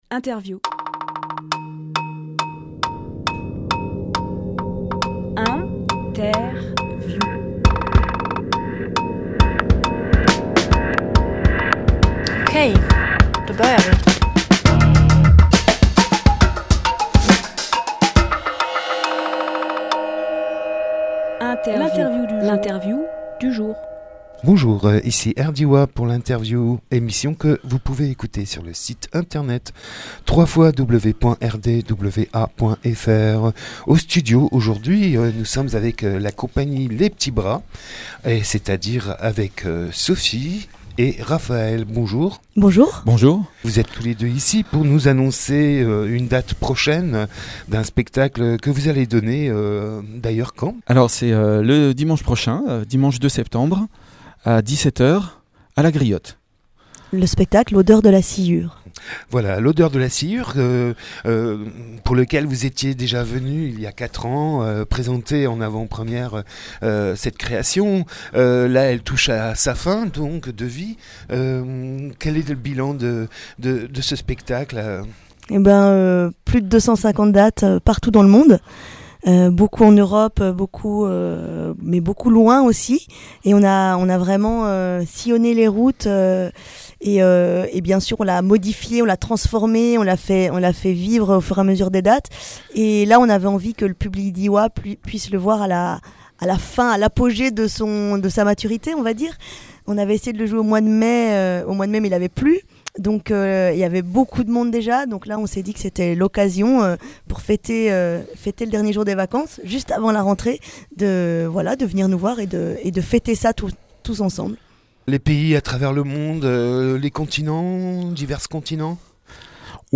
Emission - Interview Les P’tits Bras à la Griotte Publié le 28 août 2018 Partager sur…
Lieu : studio RDWA